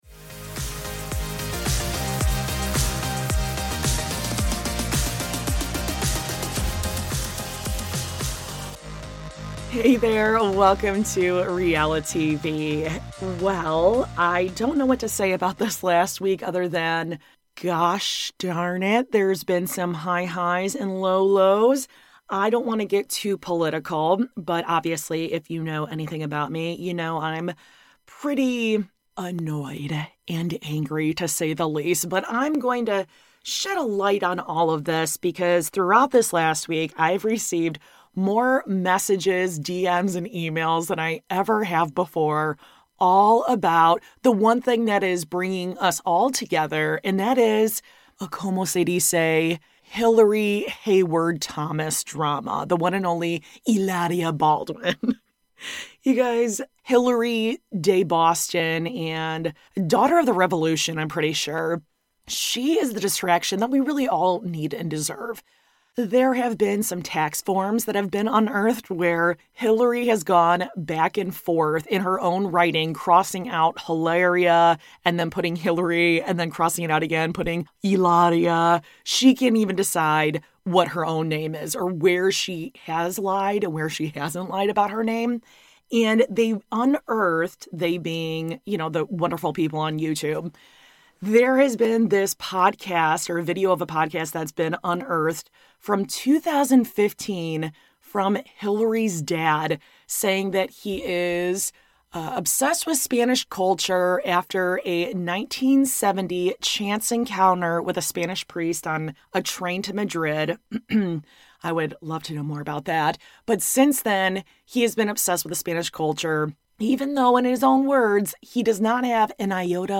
Also, Gayle King experienced a millennial Karen on live TV, and I’m waiting for Oprah to seek her revenge. You’ll hear some sweet sound clips this week, so get excited!